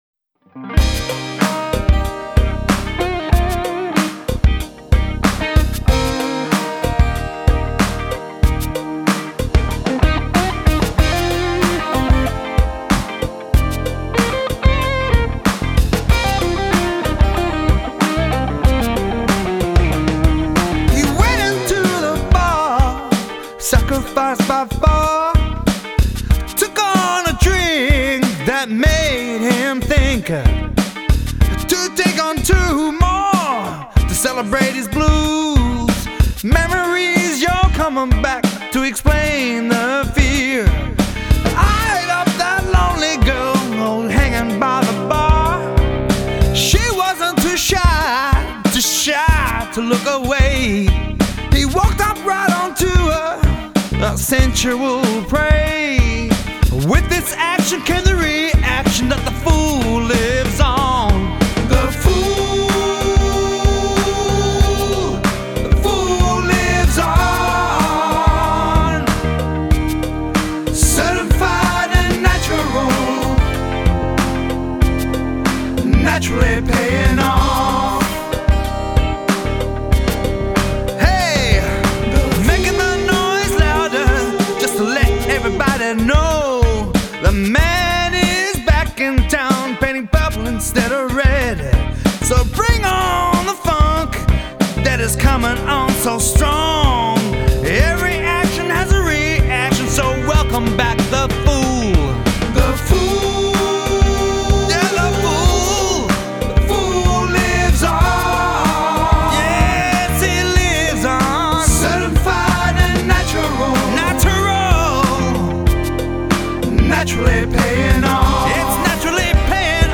Drums
Fretless Bass
Keyboards.